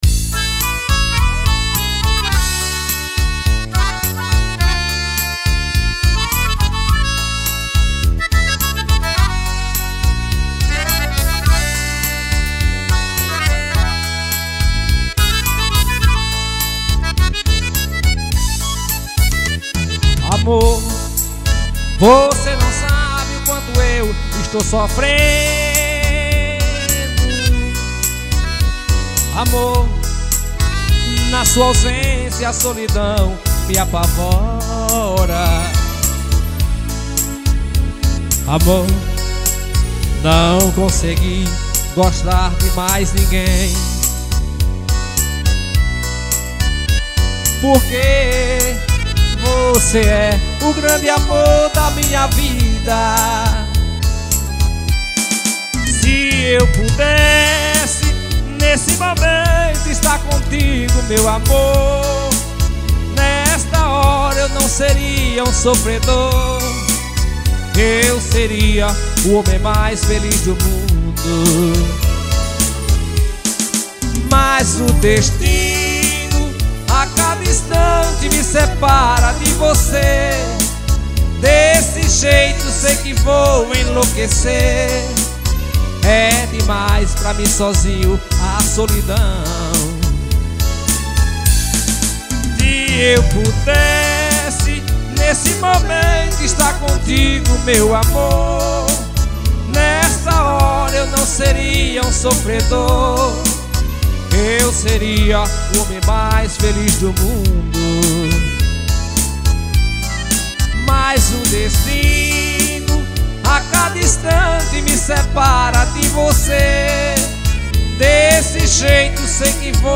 Play Brega